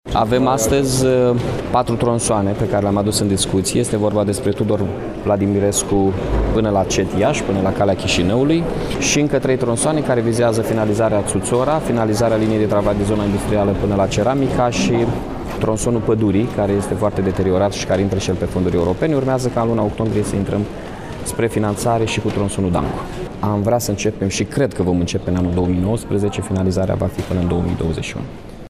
Primarul Mihai Chirica s-a referit şi la lucrări care vor fi efectuate pe artere de circulaţie din municipiul Iaşi: